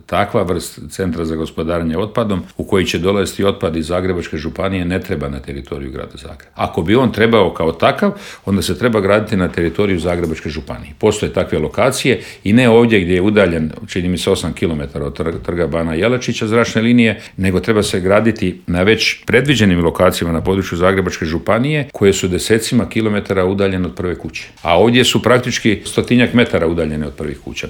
ZAGREB - Predsjednik stranke Plavi Grad, zastupnik u Gradskoj skupštini i kandidat za gradonačelnika Grada Zagreba Ivica Lovrić u Intervjuu Media servisa osvrnuo se na na ključne gradske probleme poput opskrbe plinom, Jakuševca i prometnog kolapsa.